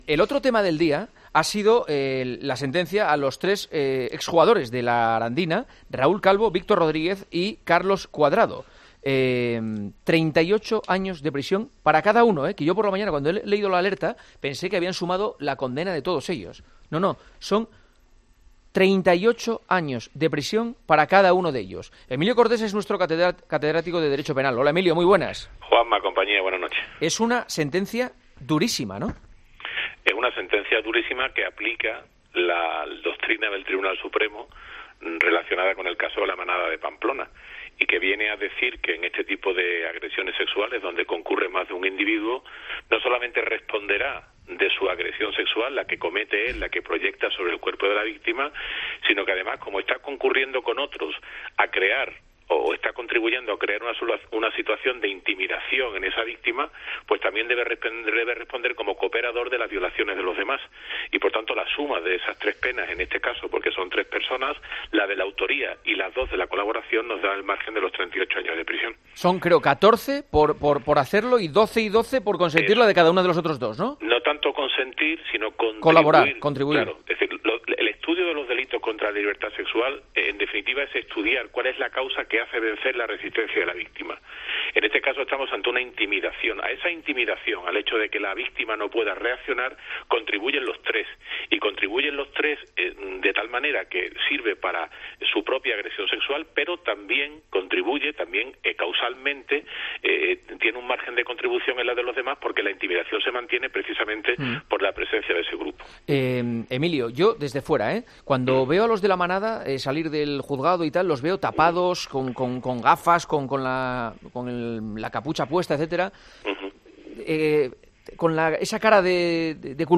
catedrático de Derecho Penal, explica en El Partidazo de COPE la condena contra los jugadores de la Arandina.